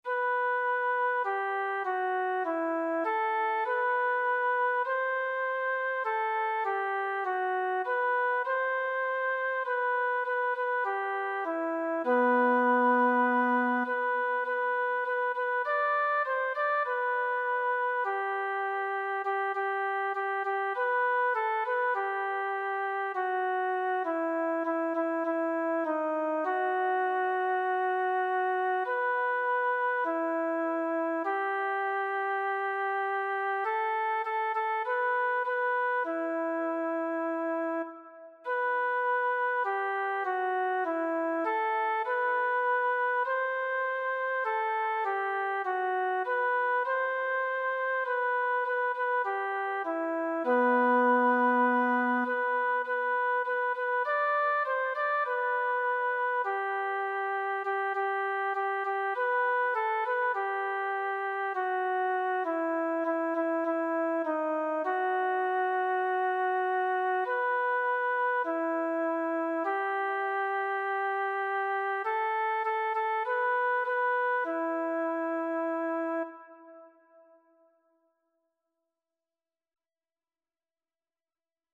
“Melodía de viva voz y con variaciones„